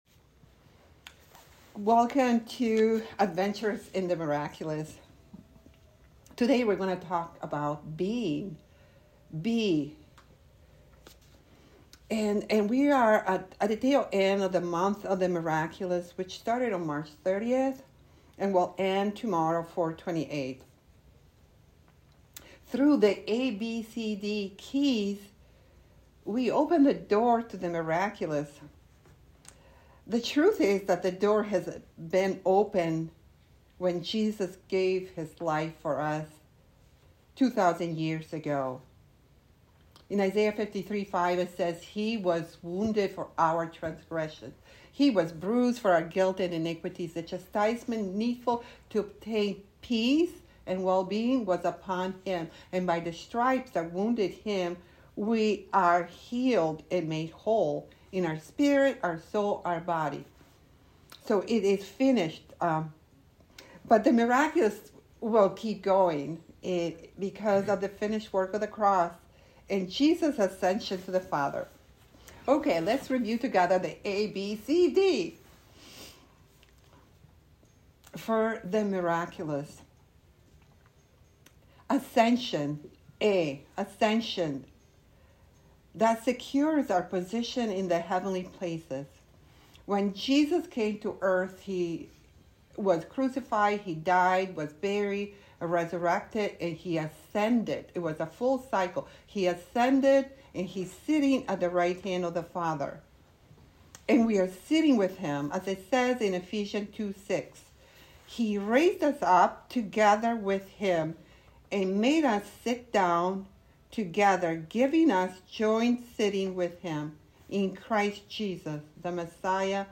Join us for week 2 of Adventures In The Miraculous Sunday School.
Adventures In The Miraculous Service Type: Class Join us for week 2 of Adventures In The Miraculous Sunday School.